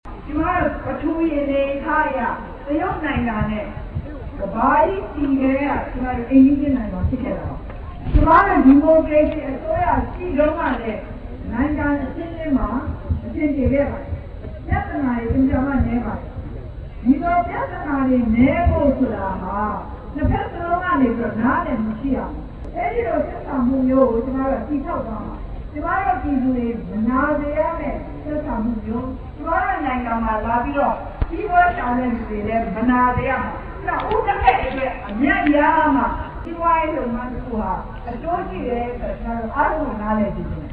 ပြည်သူတွေရဲ့အကျိုးကို ဦးစားပေး လုပ်ဆောင်တဲ့နေရာမှာ NLD အနေနဲ့ တခြား နိုင်ငံသားတွေရဲ့ အကျိုးစီးပွား ထိခိုက် နစ်နာစေတာမျိုးကို ရှောင်ရှားမှာဖြစ်ကြောင်း မနေ့က လူထုဟောပြောပွဲမှာ ထည့်သွင်း ပြောဆိုခဲ့တာပါ။
ဒေါ်အောင်ဆန်းစုကြည် ပြောကြားချက်။